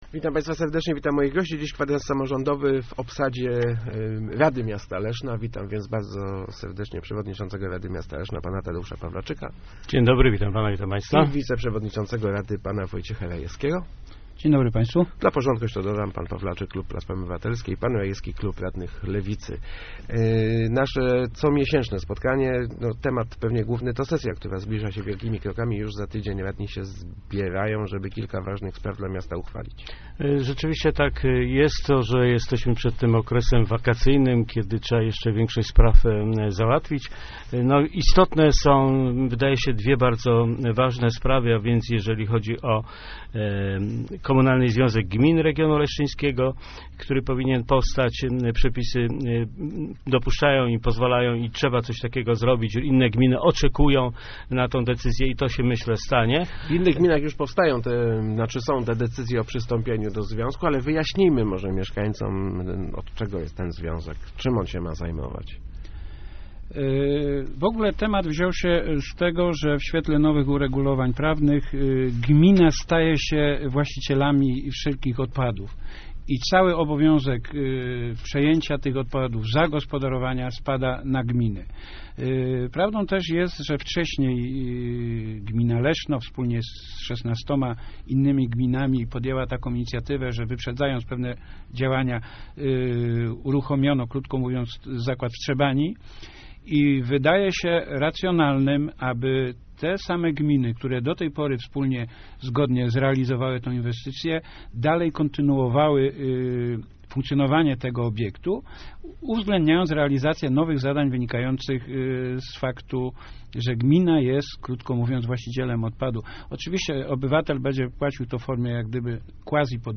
Gośćmi Kwadransa Samorządowego są Tadeusz Pawlaczyk i Wojciech Rajewski, przewodniczący i wiceprzewodniczący Rady Miasta Leszna.